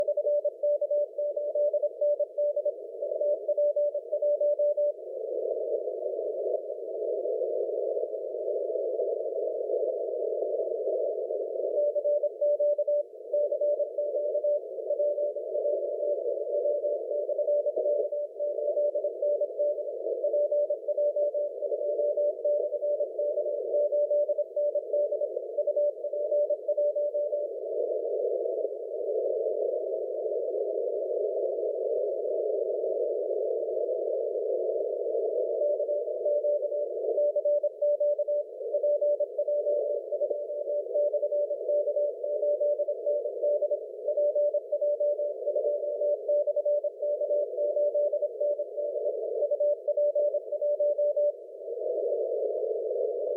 80M CW